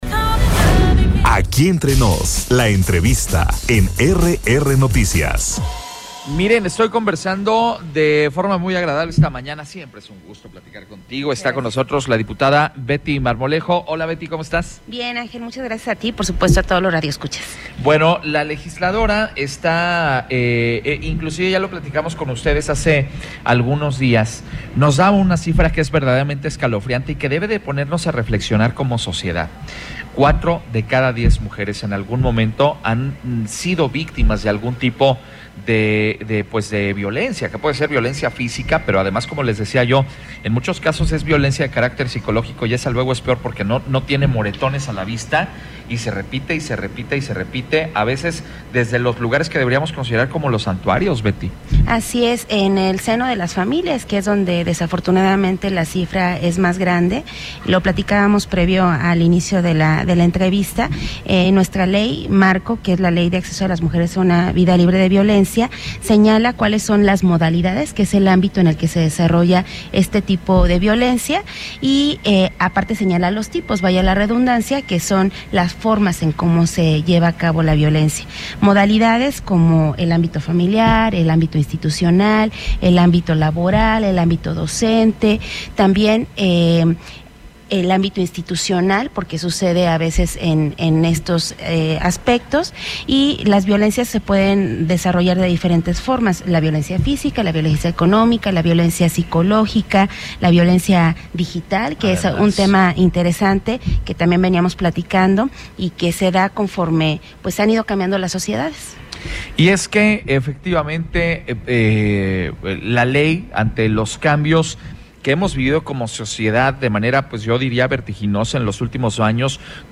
ENTREVISTA-DIPUTADA-BETY-MARMOLEJO.mp3